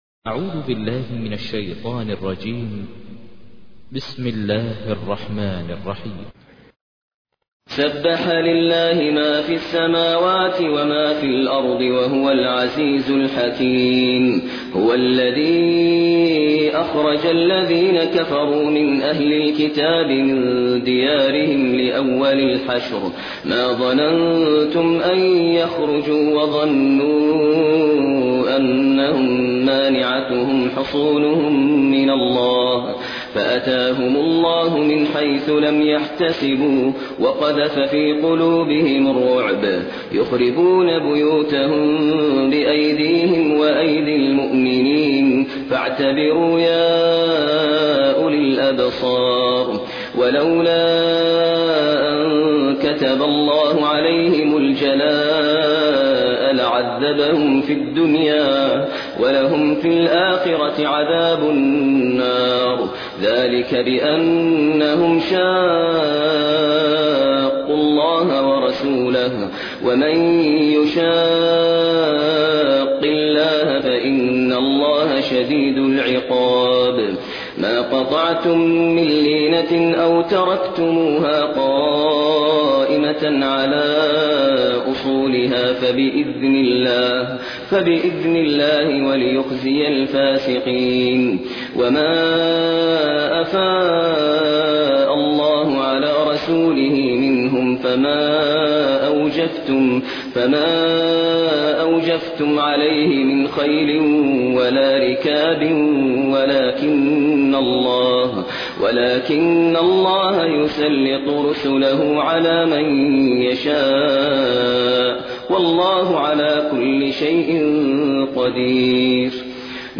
تحميل : 59. سورة الحشر / القارئ ماهر المعيقلي / القرآن الكريم / موقع يا حسين